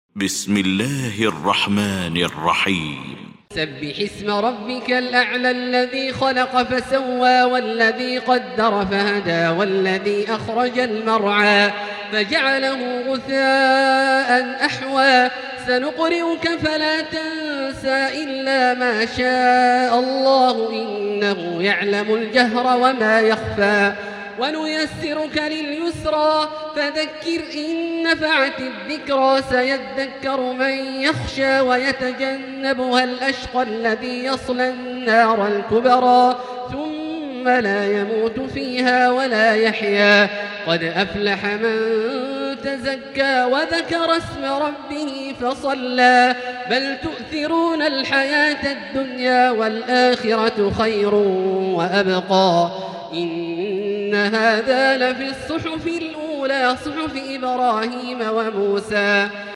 المكان: المسجد الحرام الشيخ: فضيلة الشيخ عبدالله الجهني فضيلة الشيخ عبدالله الجهني الأعلى